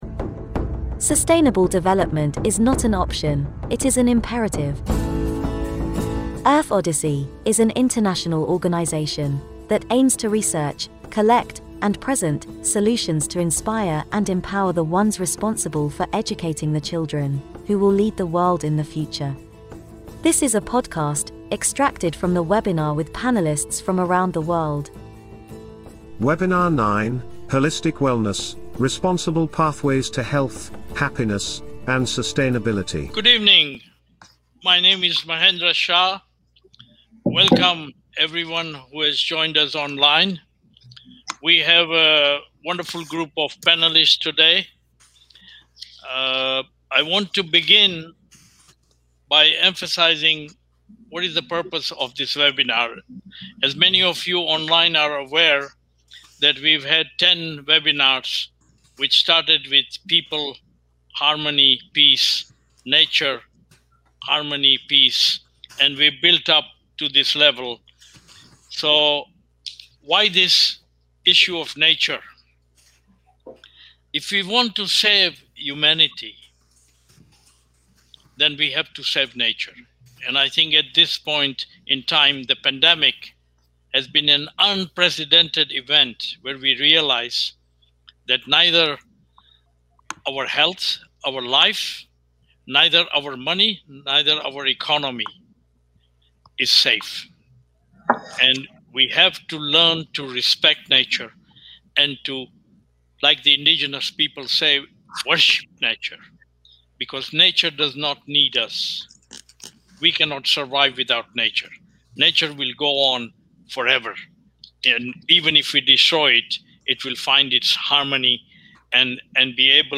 Webinar: The Imperative for Nature Rights…A Global Citizen movement – Podcast 10